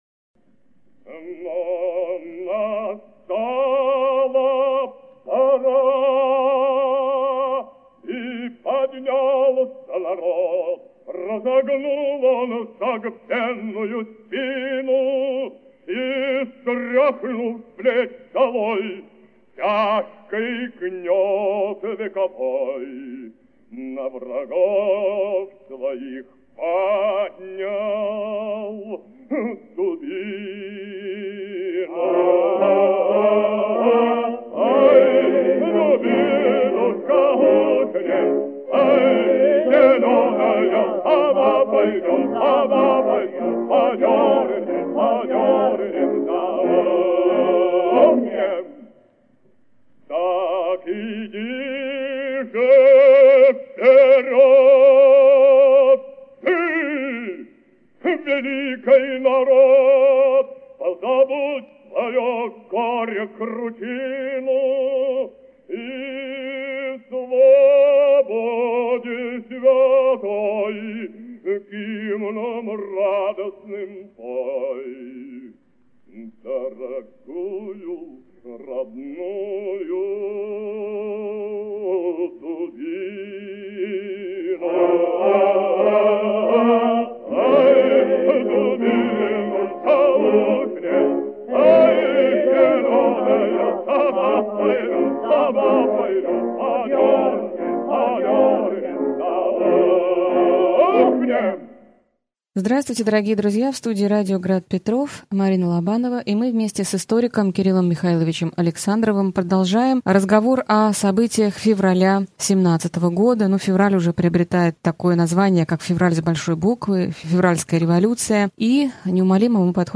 Аудиокнига Февральская революция и отречение Николая II. Лекция 22 | Библиотека аудиокниг